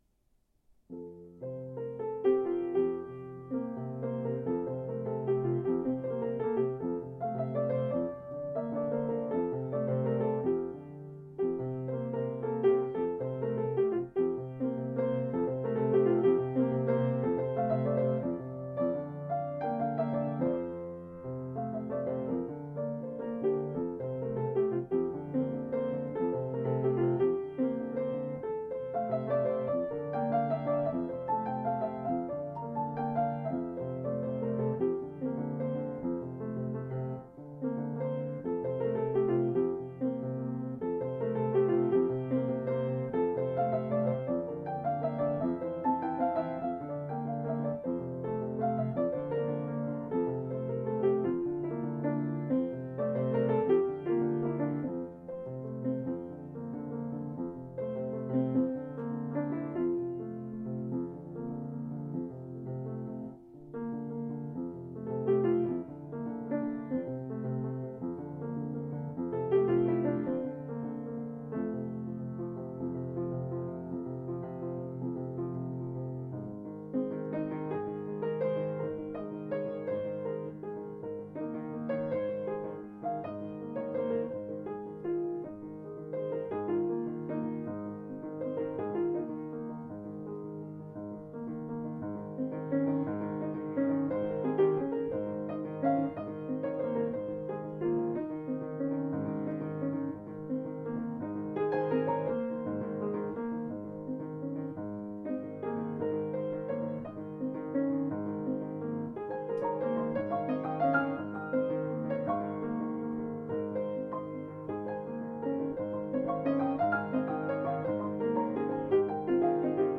improv 1-4-13
A joy to hear, and somehow extremely satisfying/relaxing!!